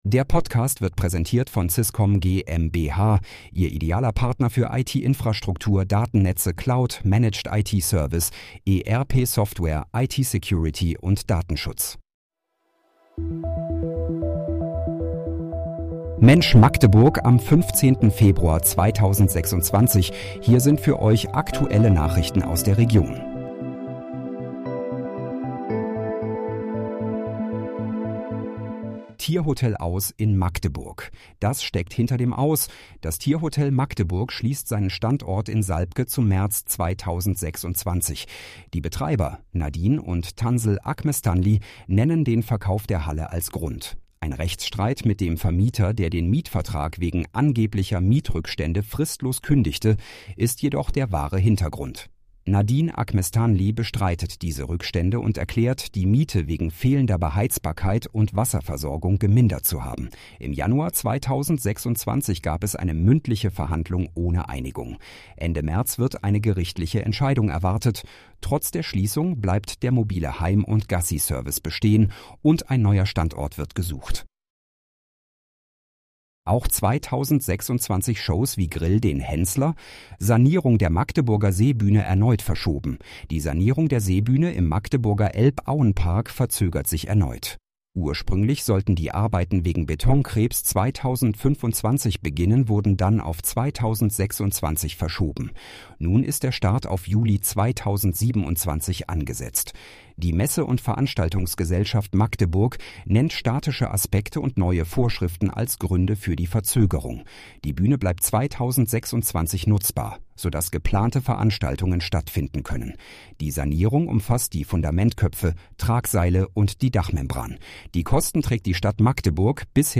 Mensch, Magdeburg: Aktuelle Nachrichten vom 15.02.2026, erstellt mit KI-Unterstützung